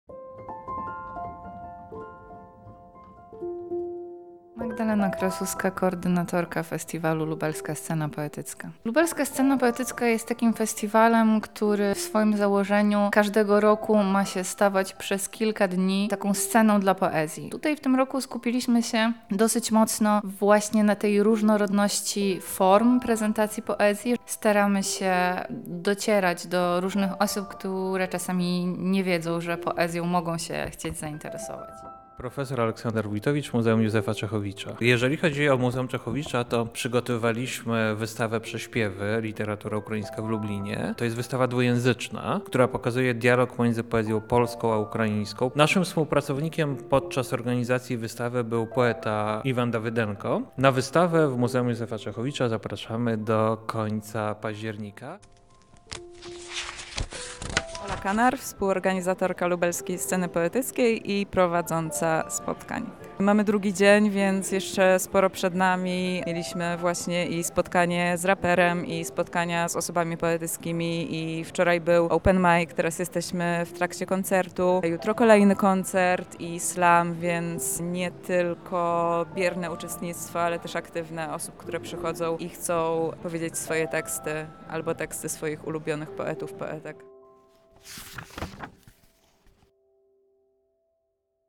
Więcej o poszczególnych punktach programu mówią uczestnicy oraz organizatorzy festiwalu.